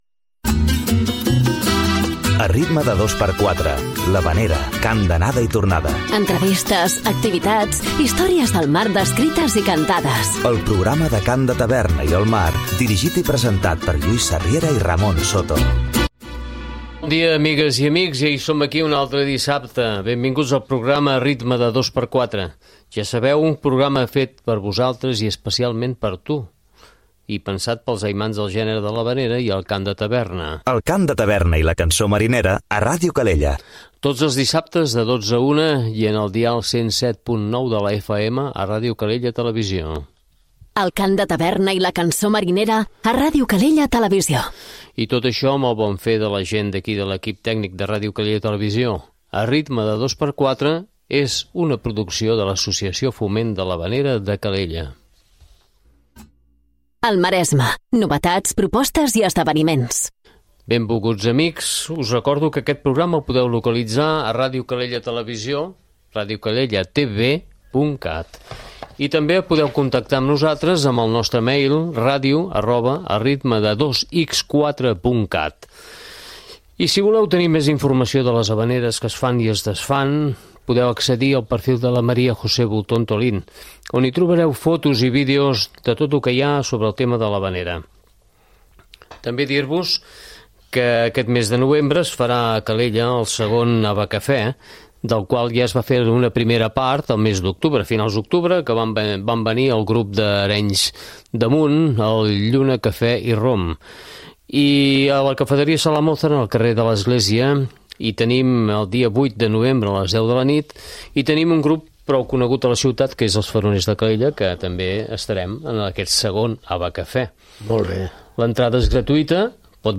soprano
guitarres que es va presentar a Calella el 2022, a la Sala Mozart.